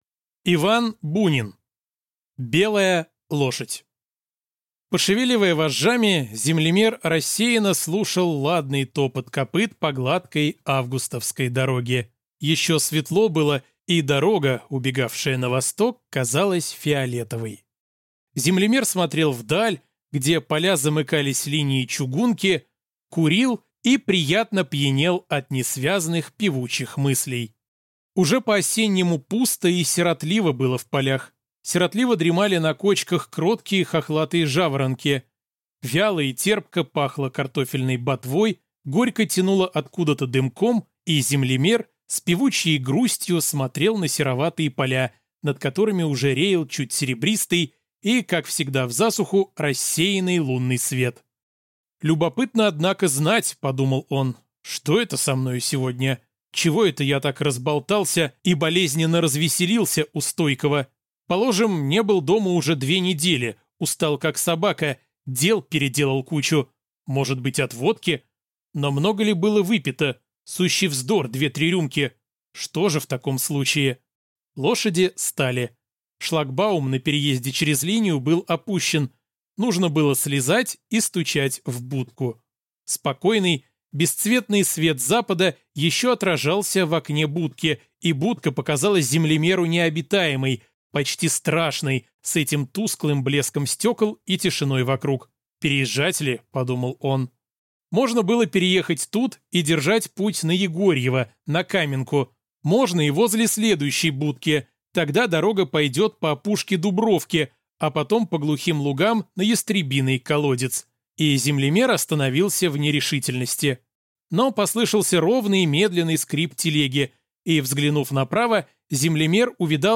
Аудиокнига Белая лошадь | Библиотека аудиокниг